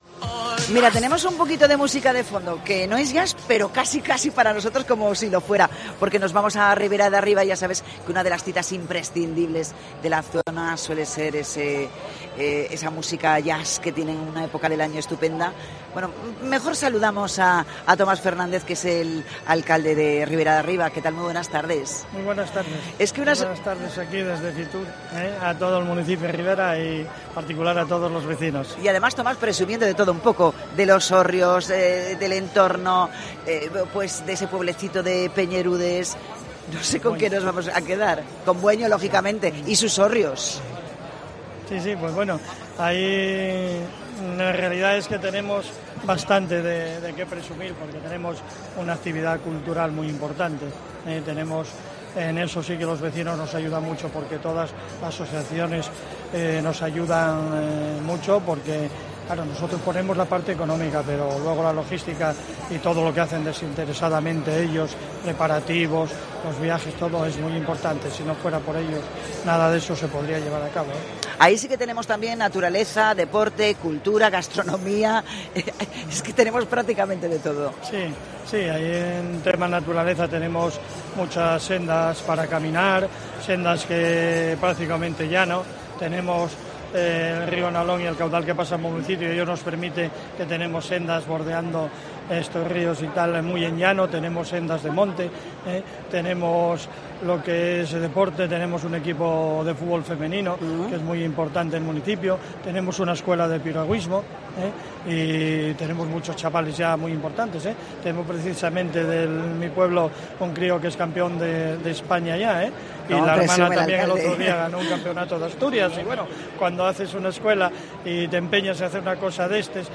En el especial de COPE Asturias con motivo de FITUR 2024, hablamos con el alcalde del concejo, Tomás Fernández: "Tenemos mucho que ofrecer, pero sin los vecinos sería imposible"
FITUR 2024: Entrevista a Tomás Fernández, alcalde de Ribera de Arriba